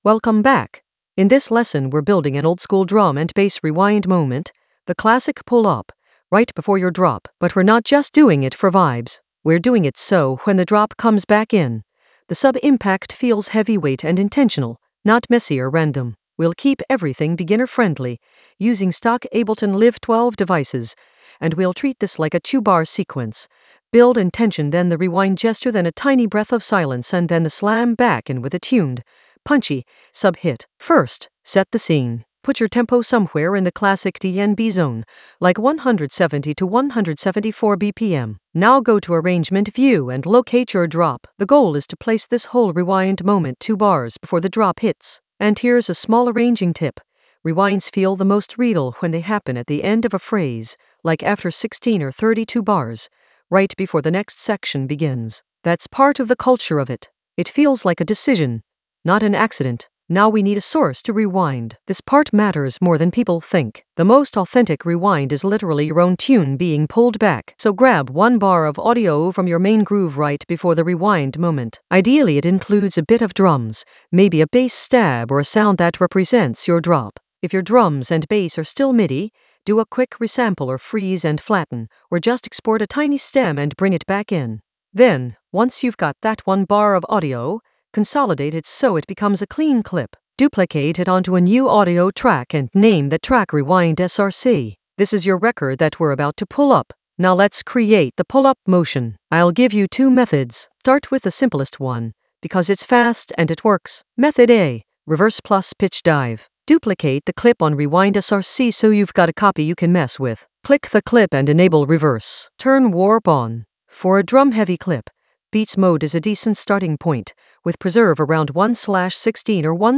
Narrated lesson audio
The voice track includes the tutorial plus extra teacher commentary.
An AI-generated beginner Ableton lesson focused on Stack oldskool DnB rewind moment for heavyweight sub impact in Ableton Live 12 in the Risers area of drum and bass production.